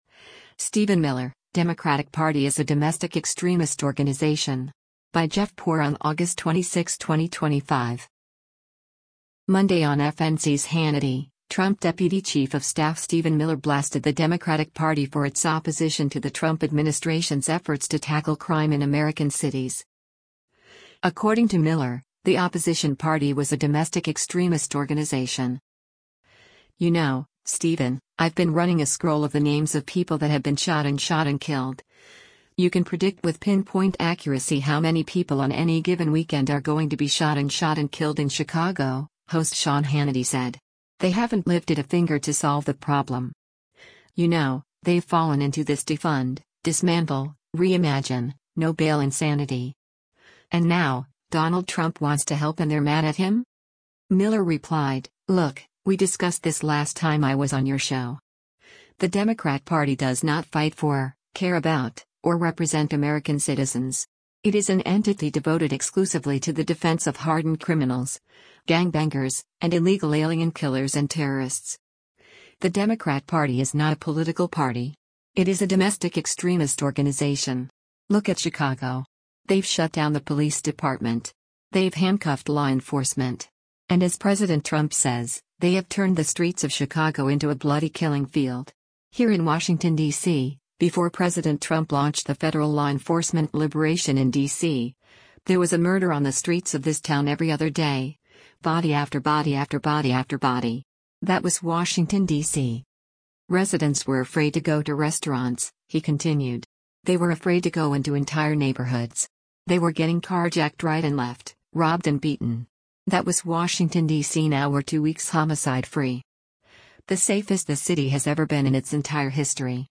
Monday on FNC’s “Hannity,” Trump deputy chief of staff Stephen Miller blasted the Democratic Party for its opposition to the Trump administration’s efforts to tackle crime in American cities.